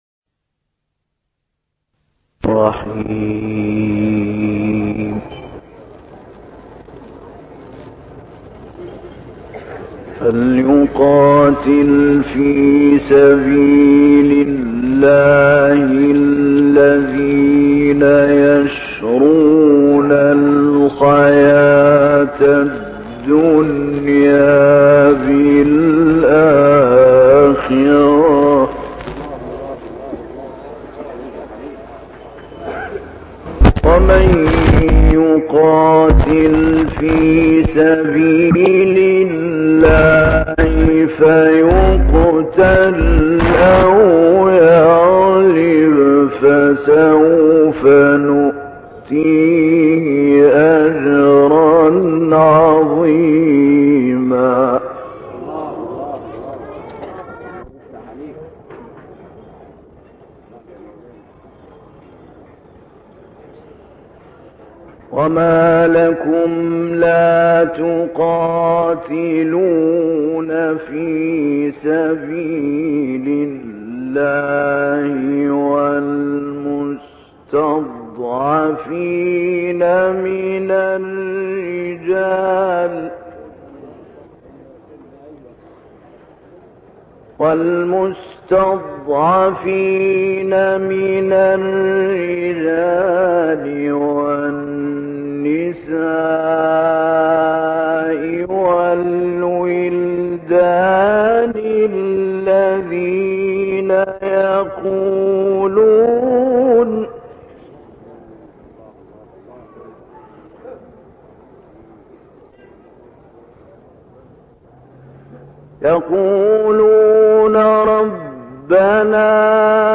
تلاوت «علی البناء» در محله سنقر
گروه شبکه اجتماعی: تلاوت آیاتی از سوره نساء با صوت محمود علی البنا اجرا شده در منطقه سنقر شهر قاهره را می‌شنوید.
این محفل تاریخی با حضور مصطفی اسماعیل در سال 1973 میلادی در منطقه سنقر شهر قاهره برگزار شده و مدت زمان این فایل صوتی 41 دقیقه است.